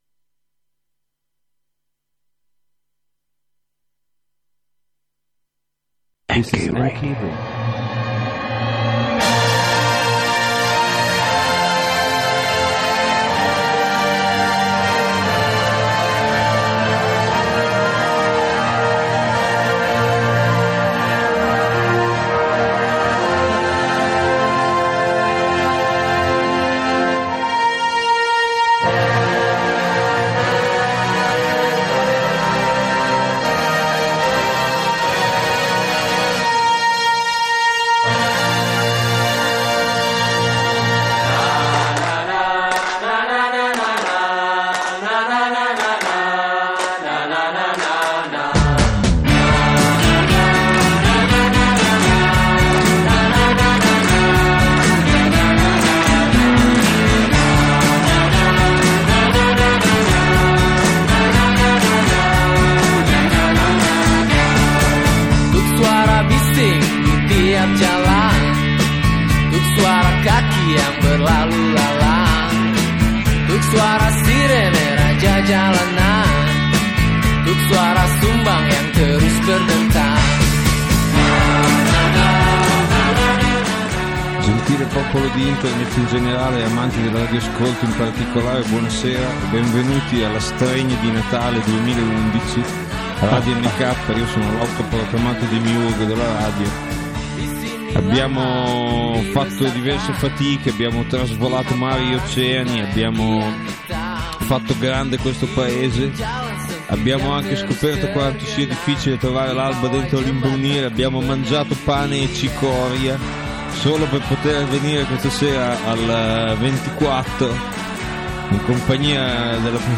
Ecco l’anno scorso s’è persa la registrazione; quest’anno no, ma insomma rendetevene conto, ascolterete un gruppo di trentenni che legge dei numeri alla radio.